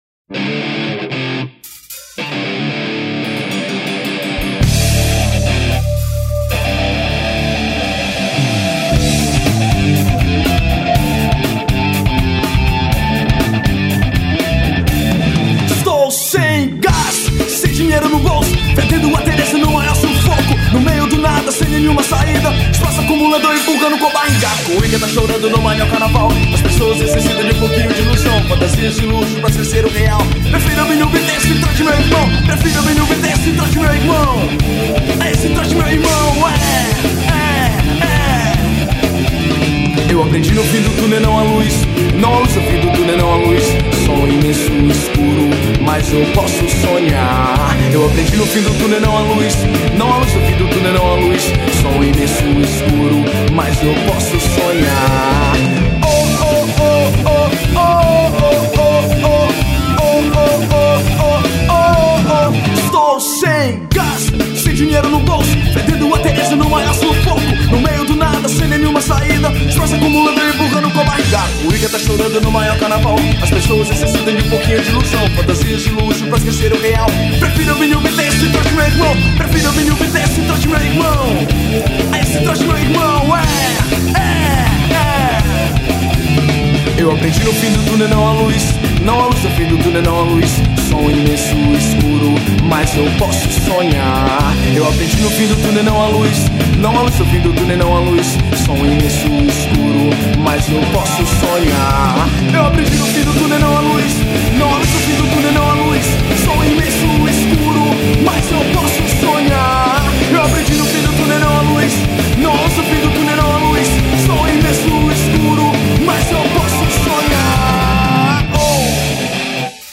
2775   02:56:00   Faixa: 9    Rock Nacional